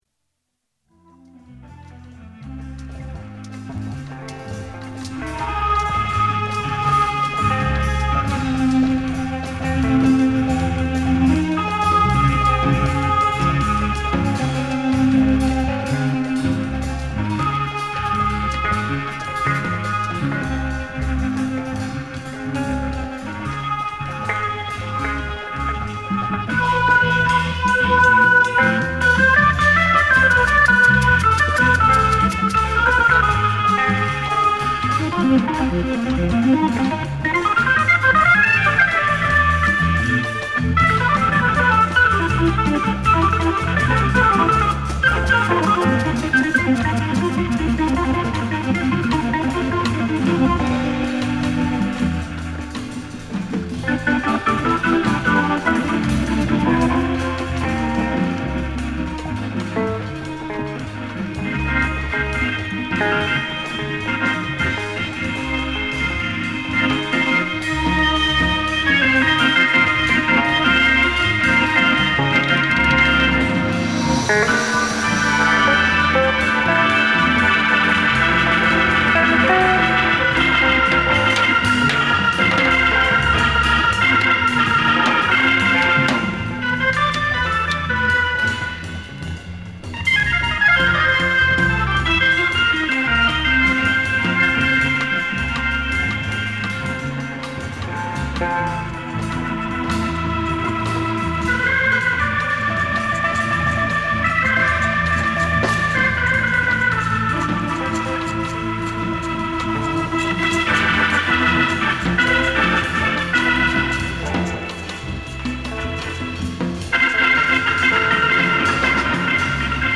Experimental Free Jazz